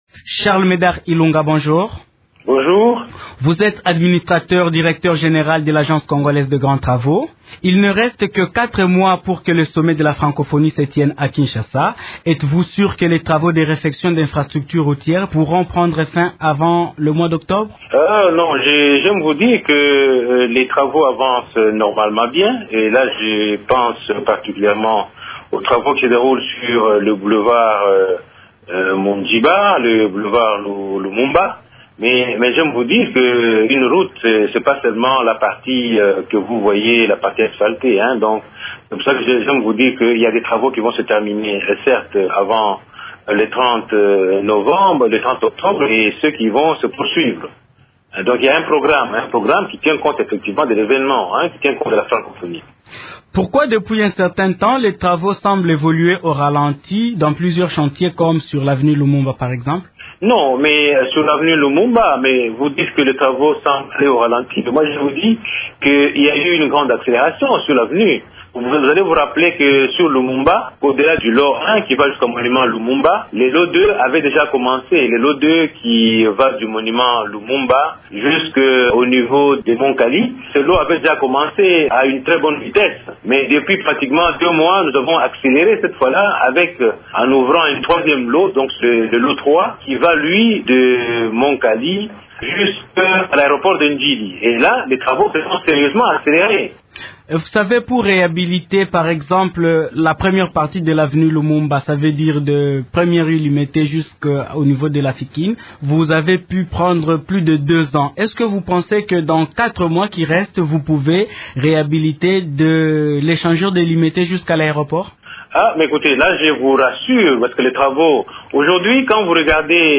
Le patron de l’Agence congolaise des Grands travaux, Charles-Médard Ilunga est l’invité de Radio Okapi ce mardi matin. Il fait le point sur la réhabilitation des infrastructures routières à quatre mois environ de la tenue du XIV sommet de la Francophonie prévu à Kinshasa du 12 au 14 octobre.